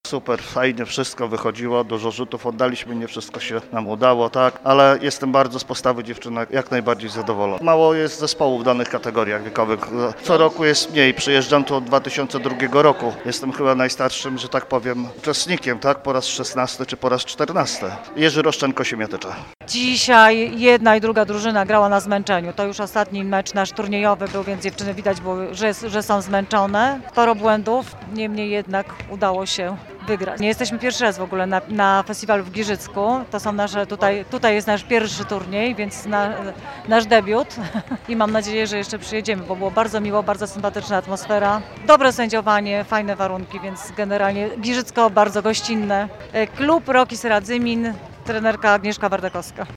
Ale zawody zorganizowane zostały na profesjonalnym poziomie – oceniają trenerzy zespołów z Siemiatycz i Radzymina.
trenerzy.mp3